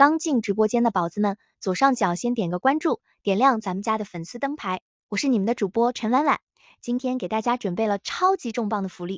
gentle_girl.wav